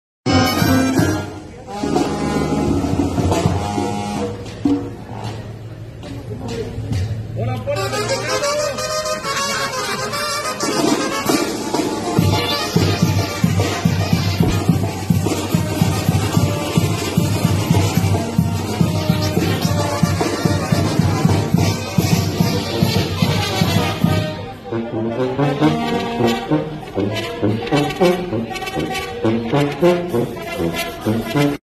Mujer celebra Mp3 Sound Effect ¡A lo grande! Mujer celebra su divorcio con banda en juzgados familiares de Toluca ¡A Lo Grande!